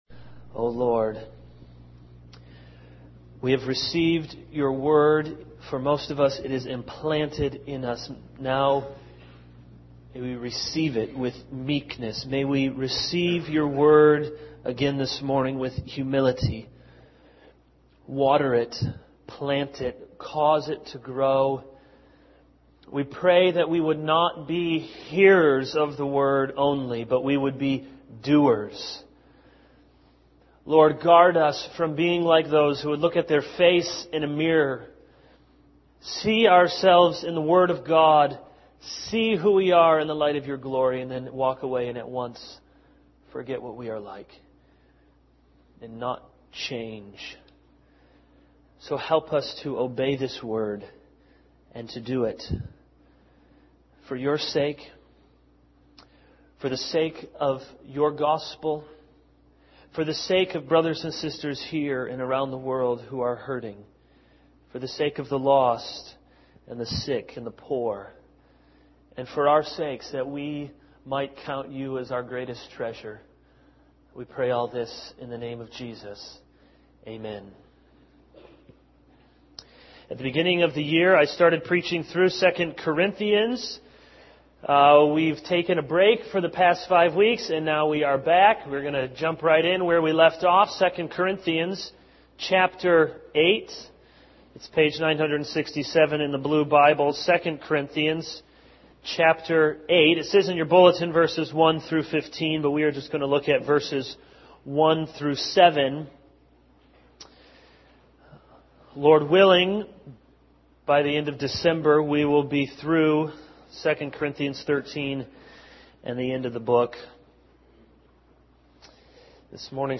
All Sermons The Gift That Keeps Giving 0:00 / Download Copied!